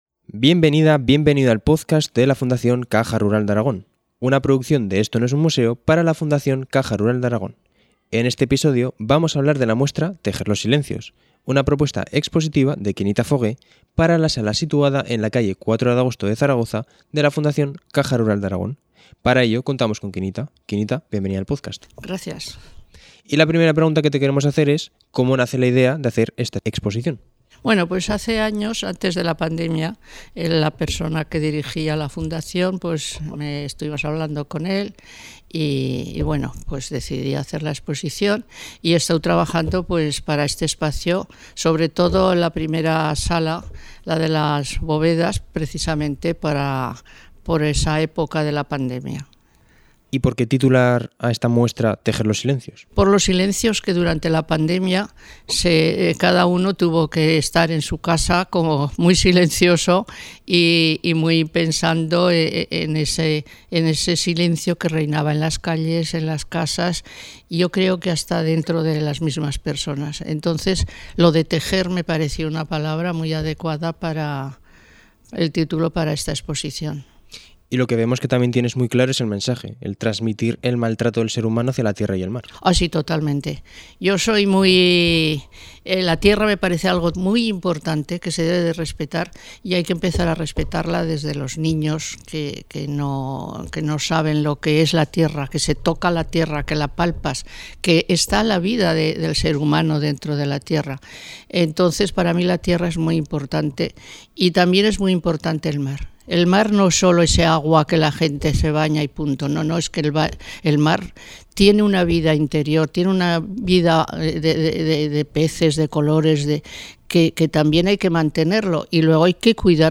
En el siguiente podcast podrán escuchar la entrevista realizada a la propia artista hablando sobre esta exposición: